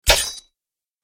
دانلود آهنگ دعوا 43 از افکت صوتی انسان و موجودات زنده
دانلود صدای دعوا 43 از ساعد نیوز با لینک مستقیم و کیفیت بالا
جلوه های صوتی